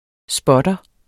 Udtale [ ˈsbʌdʌ ]